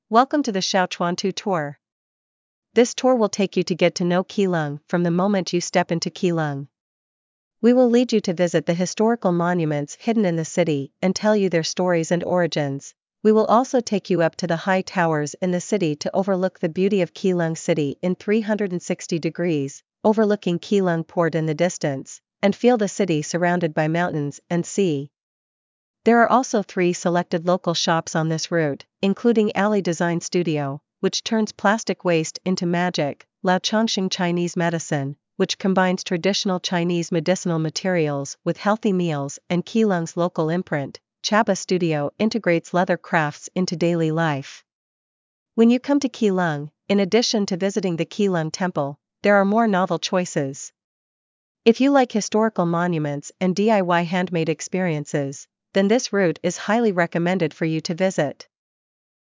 One-Minute Free Trial of This Route's Audio Guide